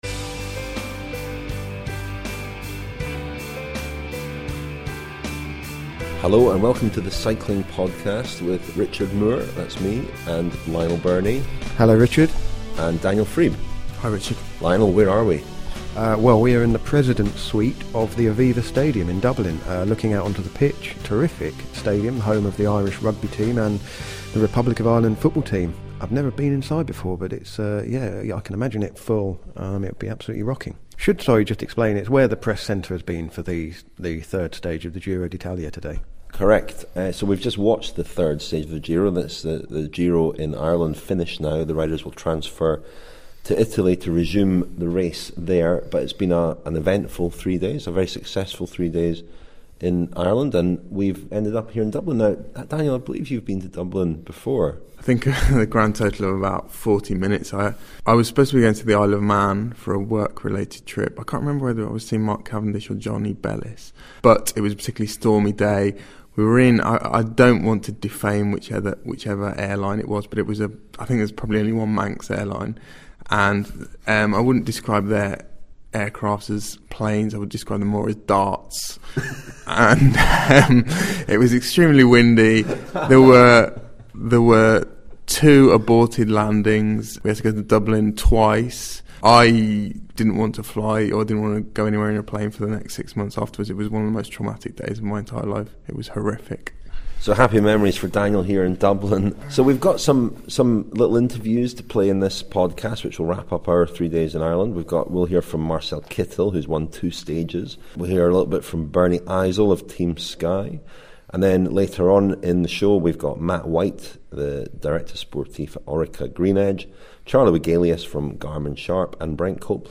There are interviews